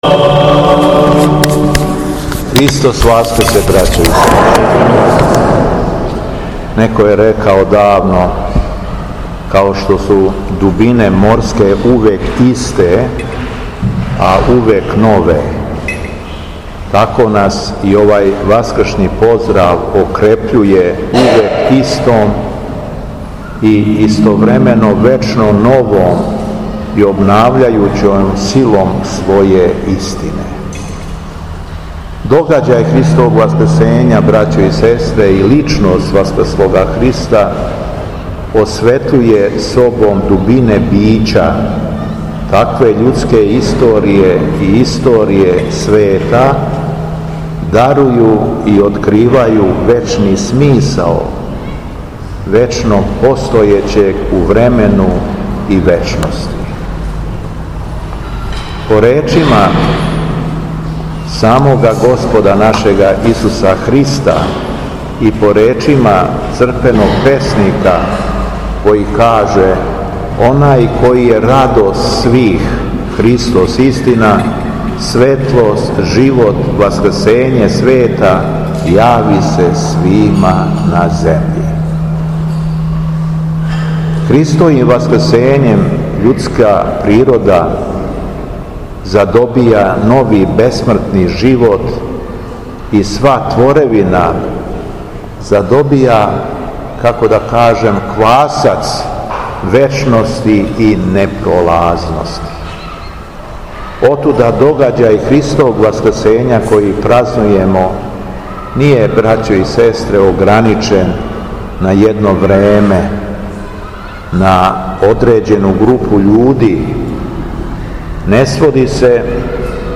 По прочитаном Јеванђељу Његово Високопреосвештенство обратило се верном народу надахнутом беседом:
Беседа Његовог Високопреосвештенства Митрополита шумадијског г. Јована